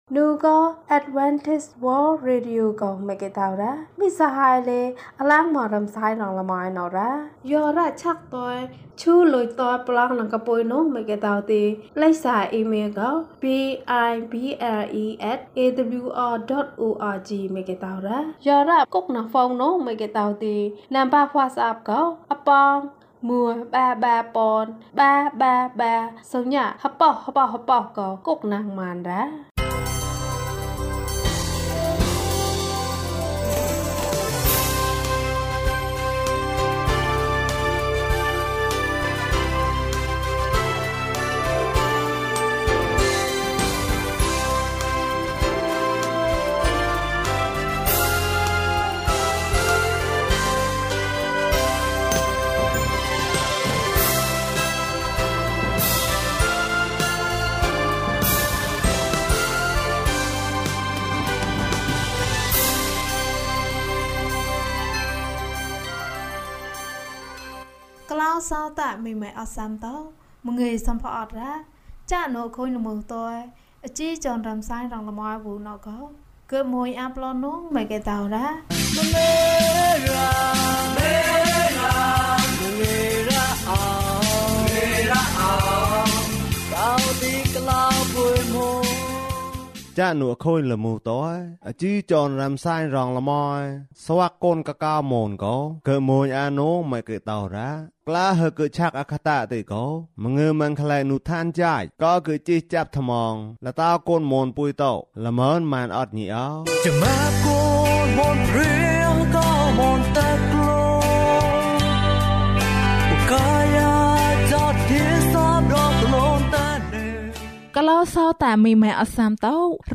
လူတွေက ယေရှုကို လိုအပ်တယ်။၀၂ ကျန်းမာခြင်းအကြောင်းအရာ။ ဓမ္မသီချင်း။ တရားဒေသနာ။